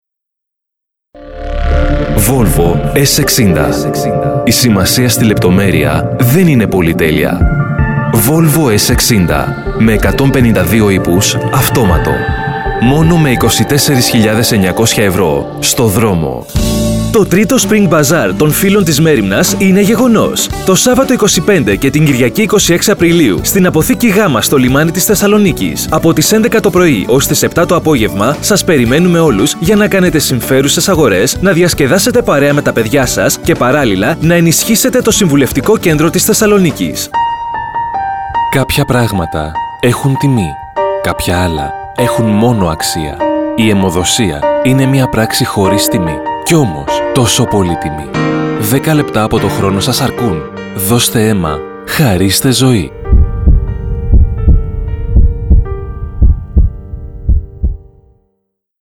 Dynamic Greek male voiceover artist, with over 10 years of experience. Can perform theatrical and cartoon roles, sing and do rap.
Sprechprobe: Industrie (Muttersprache):